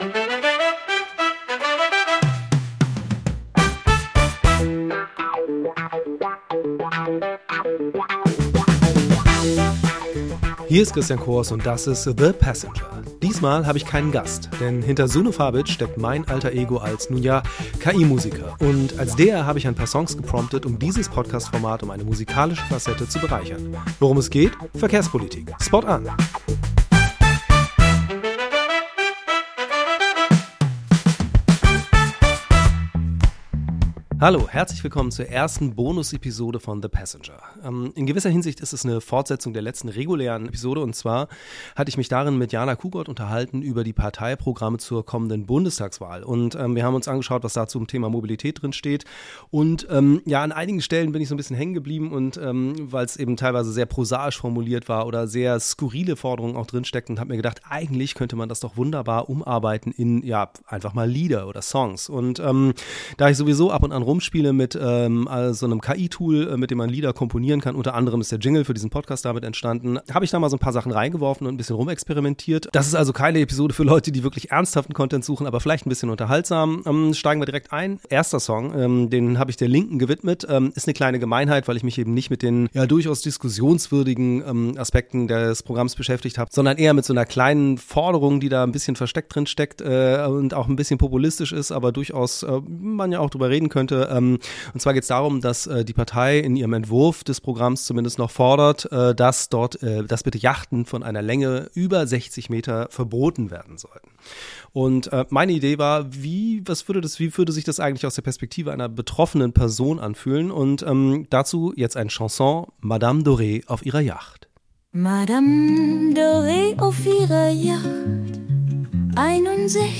KI-Musiker-Alter-Ego